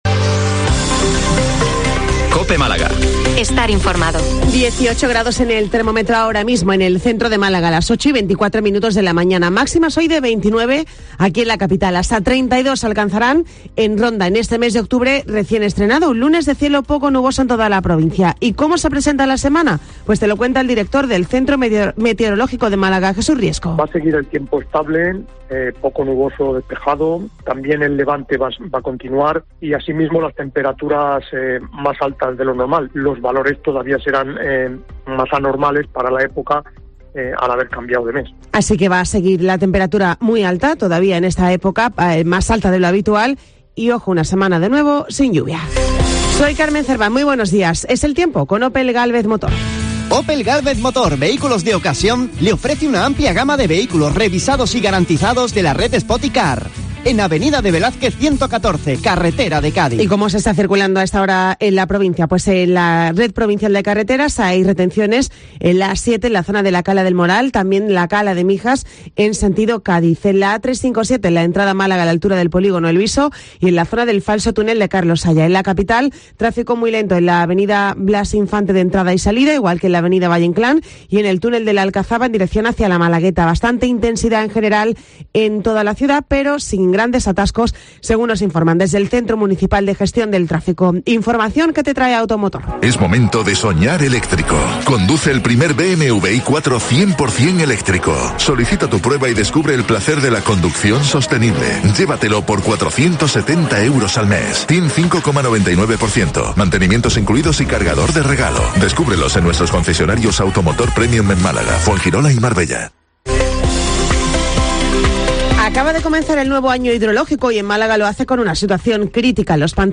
Informativo 08:24 Málaga - 021023